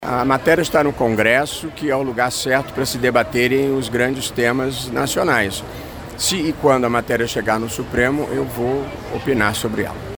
Em entrevista ao programa Correio Debate, da Rádio Correio 98 FM, o magistrado defendeu que o debate seja de competência do Congresso Nacional, mas caso chegue ao âmbito da Suprema Corte, ele opinará a respeito do tema.